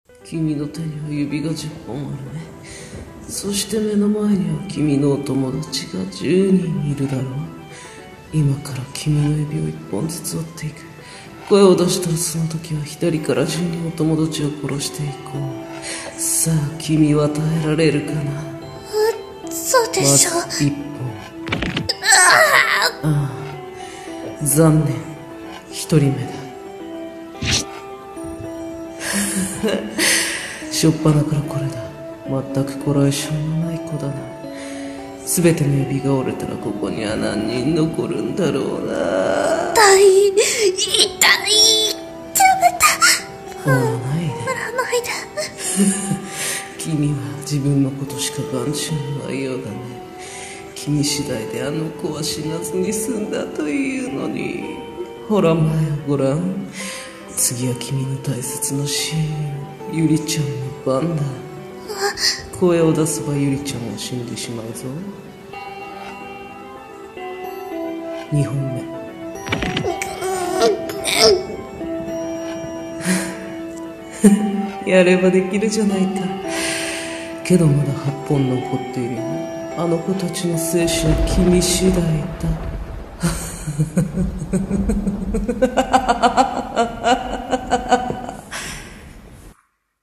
指折り殺人鬼【ホラー声劇】